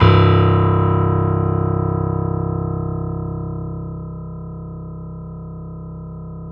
SG1 PNO  C 0.wav